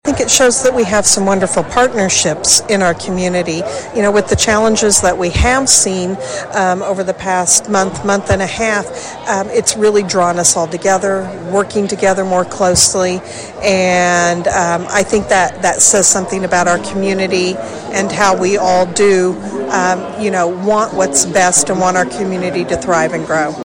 There was a celebratory atmosphere at Emporia State’s Memorial Union for the Emporia Area Chamber of Commerce’s 127th Annual Meeting and banquet Friday night.